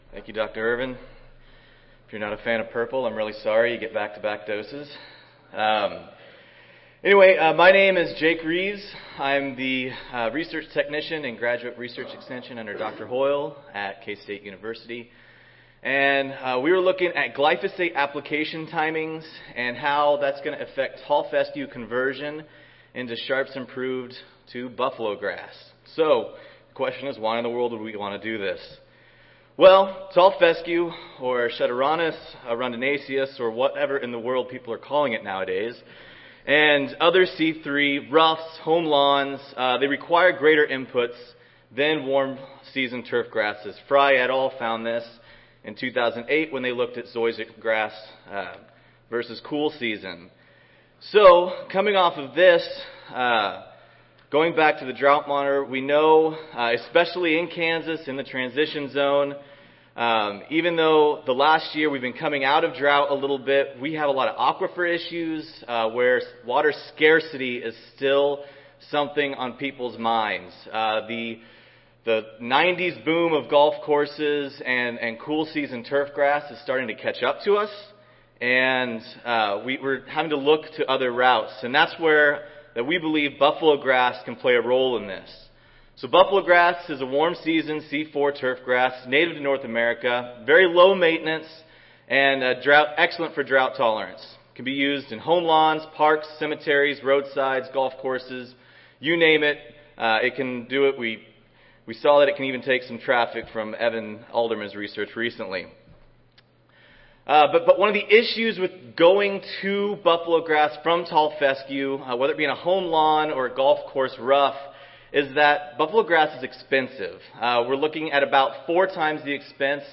Recorded Presentation